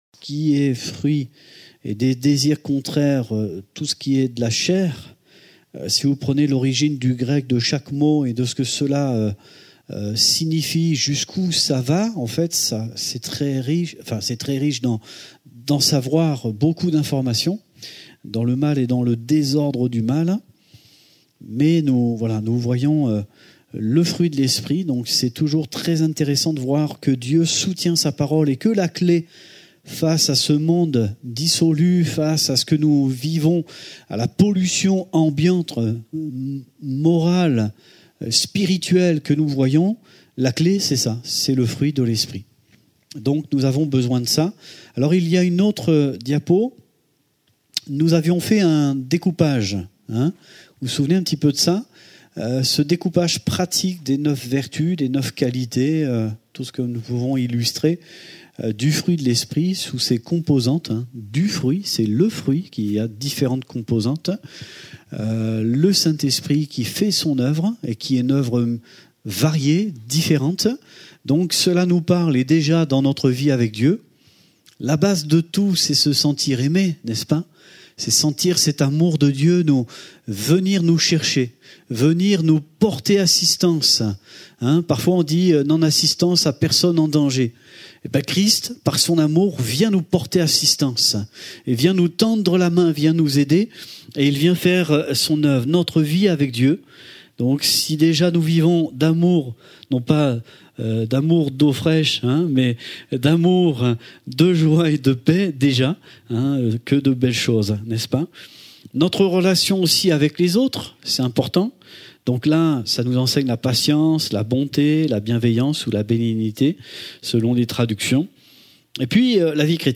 Passage: Galates 5:16-26 Type De Service: Etude Biblique « Fais moi voir Ta gloire Le Cantique du Serviteur